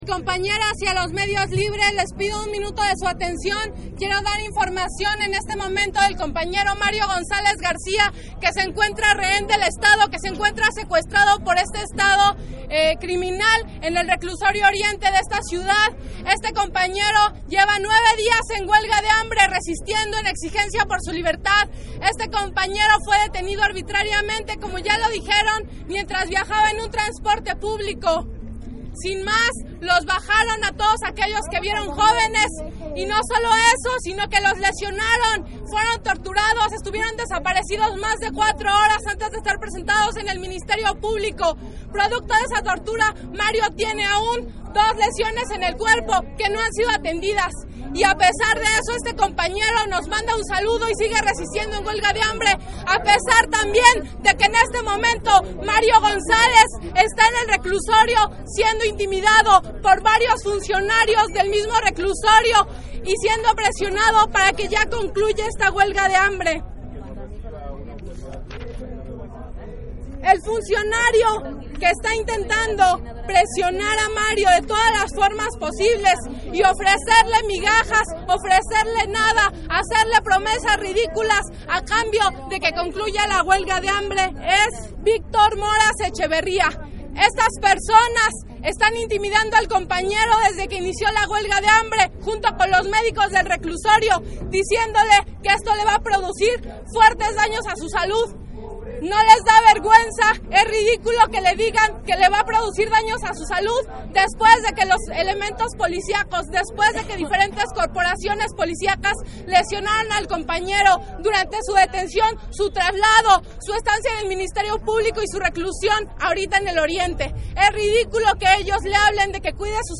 En ese mismo momento se llevó a cabo una asamblea, la cual se negó a la simulación de diálogo, mientras tanto, el GDF reitero que no se permitiría el paso para llegar a sus oficinas GDF.